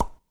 plop.wav